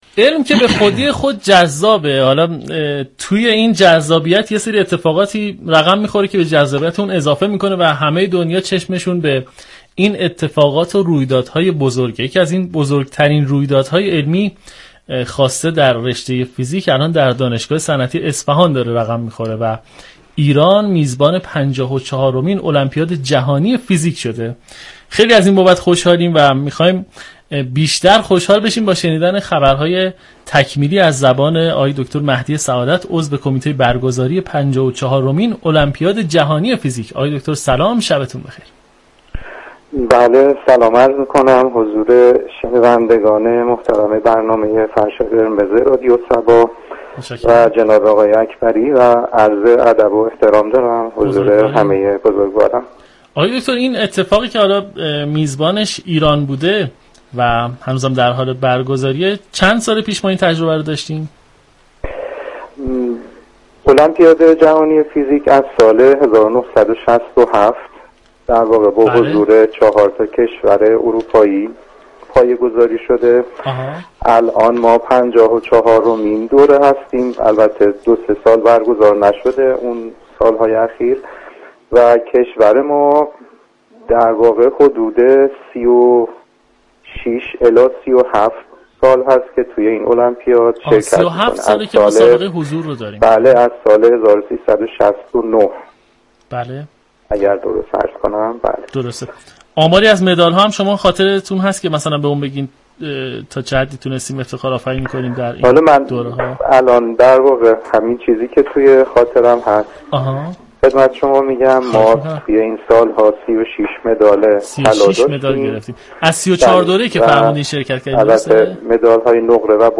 دریافت فایل به گزارش روابط عمومی رادیو صبا، «فرش قرمز» عنوان یكی از برنامه‌های گفتگو محور این شبكه رادیویی است كه با هدف معرفی نخبگان علمی كشور همراه مخاطبان می‌شود.